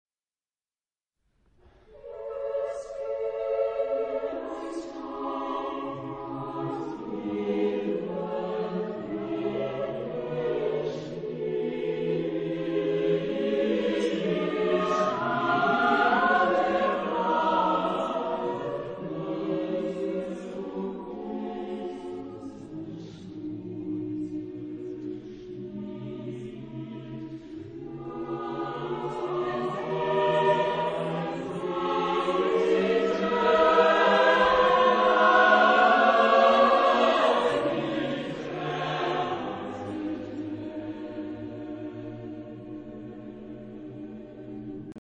Genre-Stil-Form: Motette ; geistlich
Chorgattung: SSATB  (5 gemischter Chor Stimmen )
Tonart(en): A-Dur
von Stuttgarter Kantorei gesungen
Aufnahme Bestellnummer: 7. Deutscher Chorwettbewerb 2006 Kiel